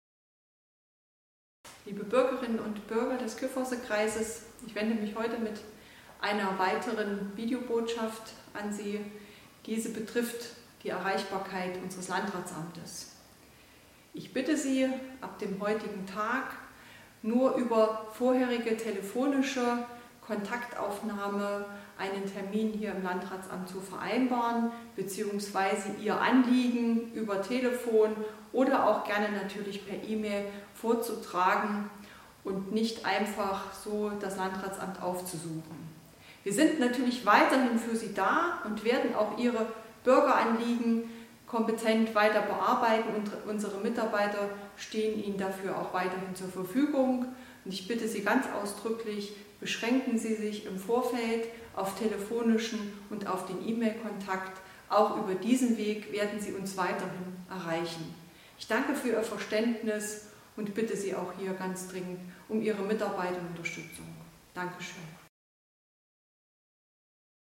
Hier die Videobotschaft in der Sprachausgabe: